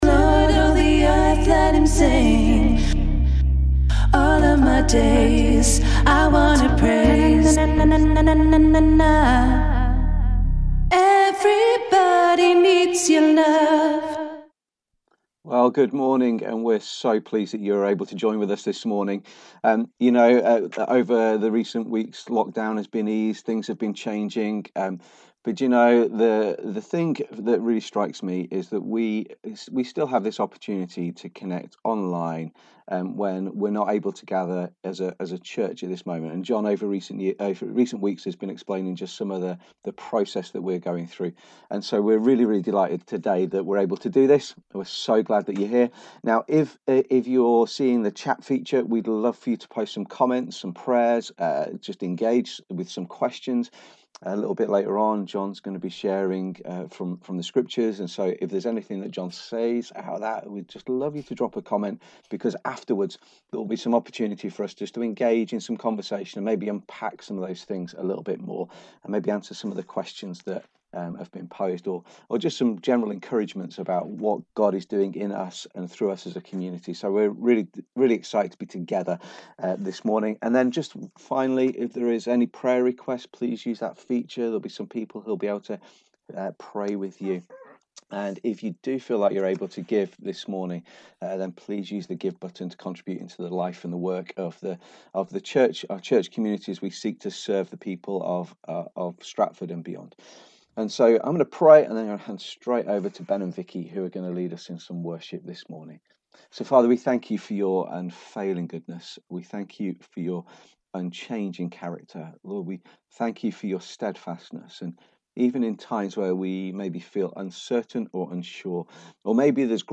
Listen again to our church online gathering.